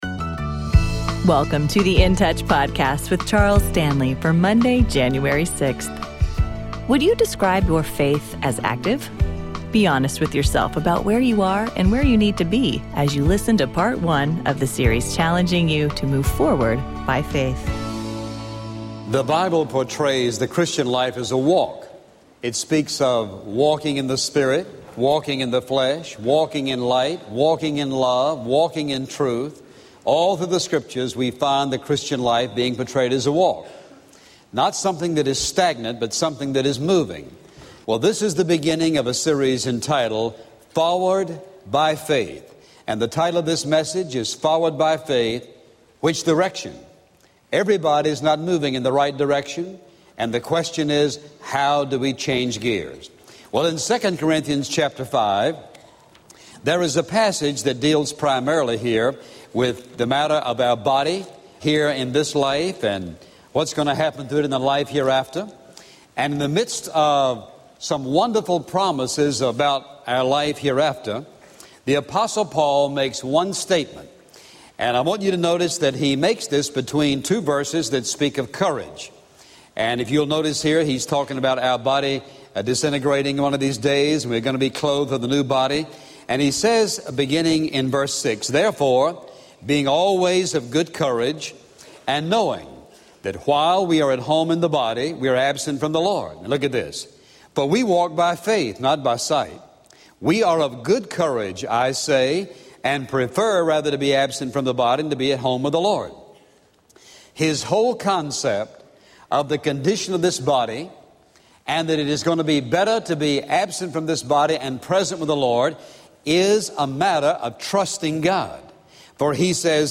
Dr. Charles Stanley and In Touch Ministries’ daily radio program.